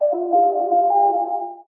unlock.ogg